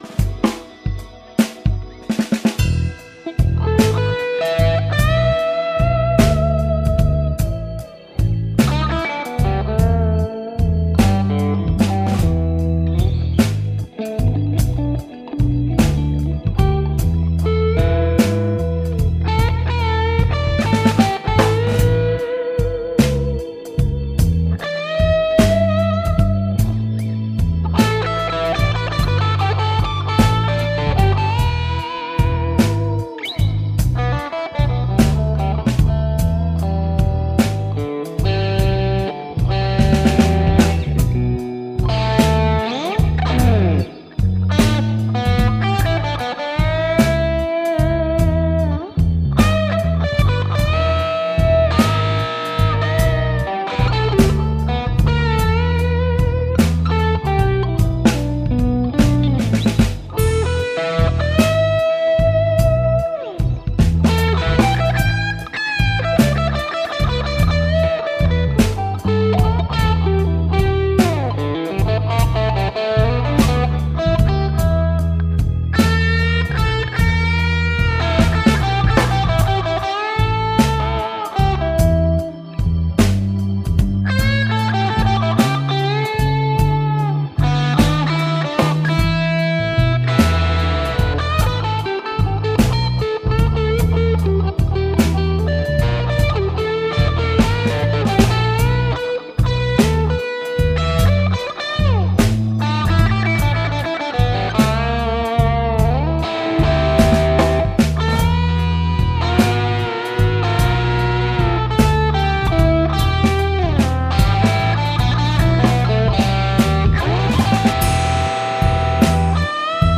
-kun osallistut, soita soolo annetun taustan päälle ja pistä linkki tähän threadiin.